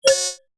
UI_SFX_Pack_61_29.wav